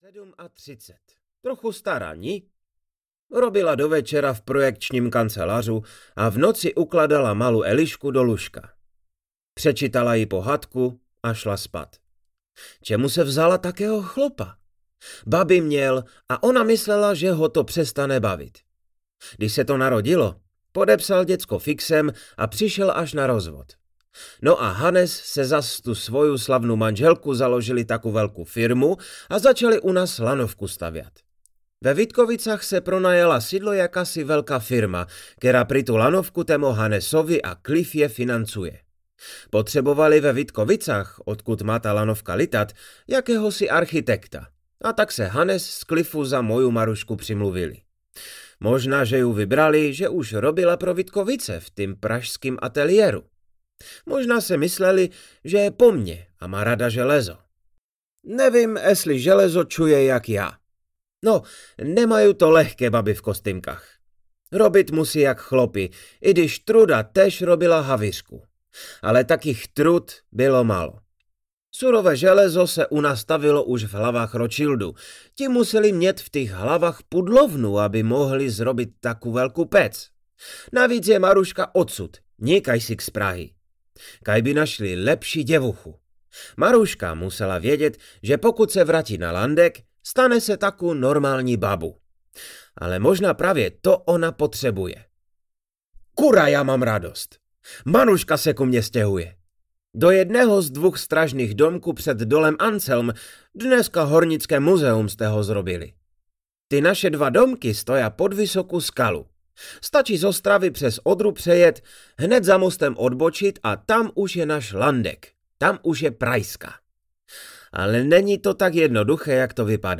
Lanovka nad Landekem audiokniha
Ukázka z knihy
Rázovitý svět Hlučínska přibližuje i prajzský dialekt.